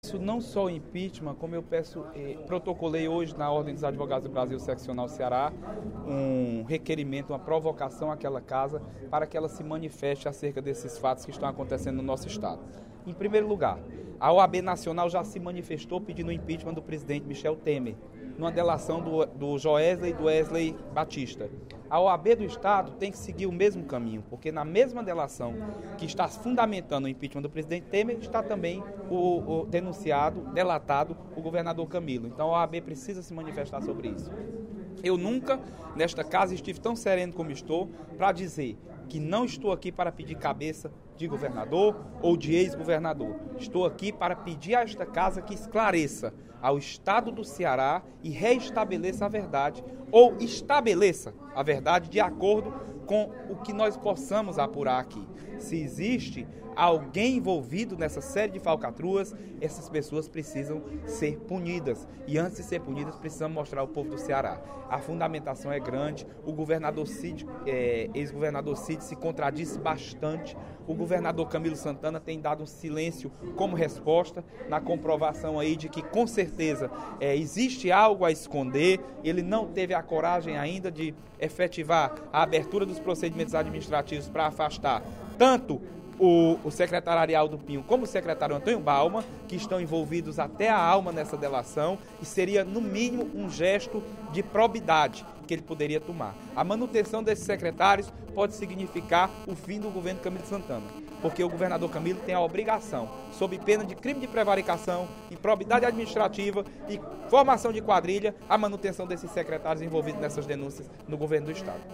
O deputado Leonardo Araújo (PMDB) pediu, nesta quinta-feira (25/05), durante o primeiro expediente da sessão plenária, o impeachment do governador Camilo Santana.